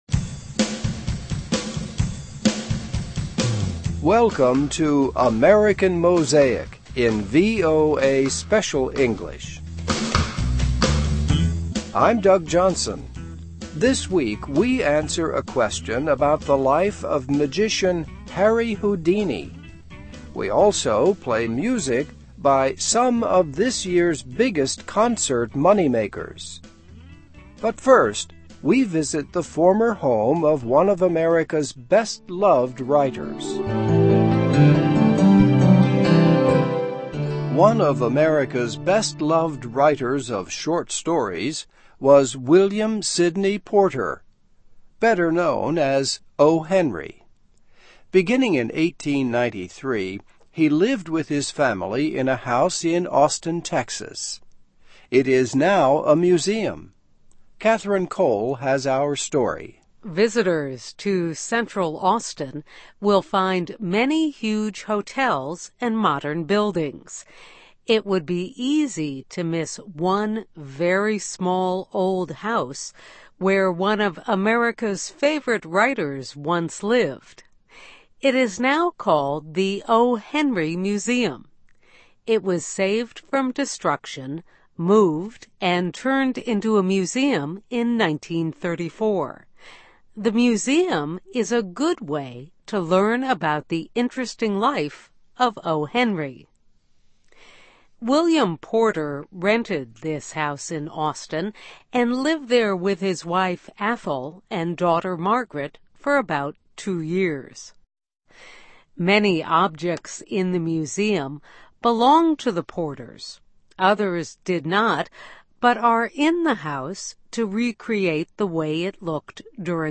Also: The life of magician Harry Houdini. And music from the most profitable touring bands of the year | AMERICAN MOSAIC